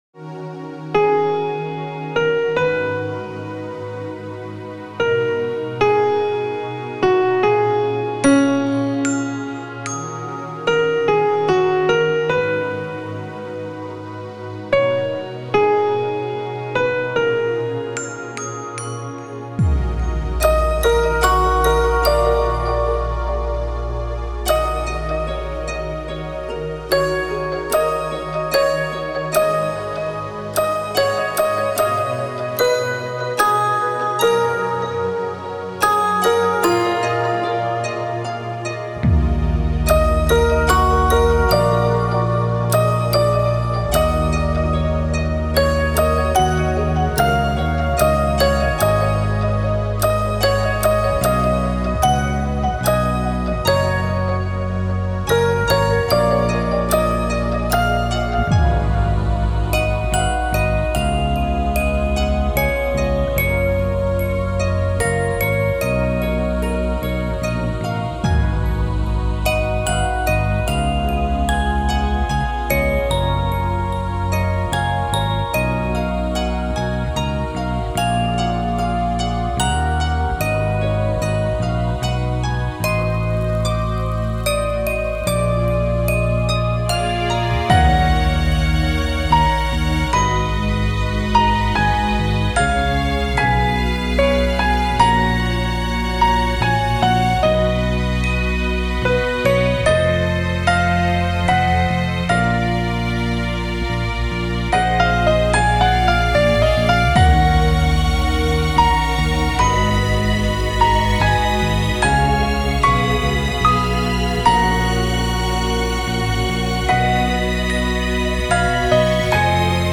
フリーBGM イベントシーン 切ない・悲しい
動画制作・ゲーム制作・配信など、さまざまなシーンでご利用いただけるフリーBGMです。
フェードアウト版のmp3を、こちらのページにて無料で配布しています。